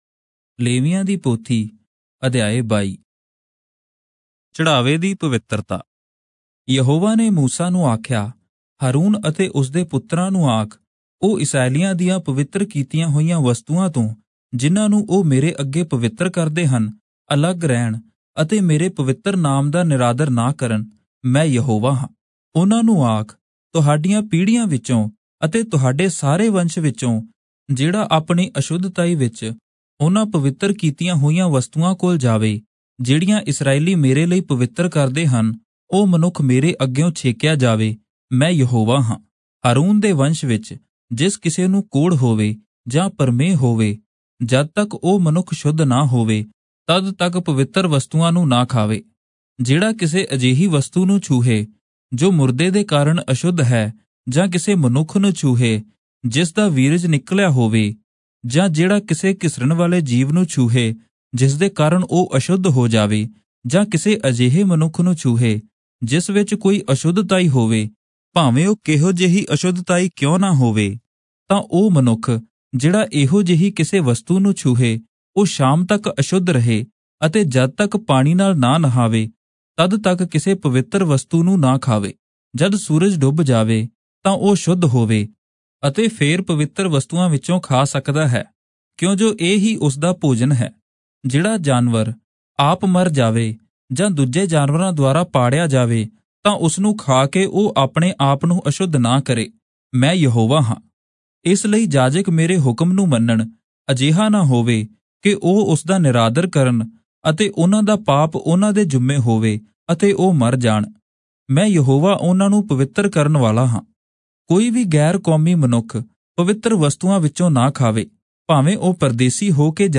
Punjabi Audio Bible - Leviticus 1 in Irvpa bible version